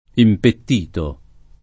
impettito [ impett & to ] agg.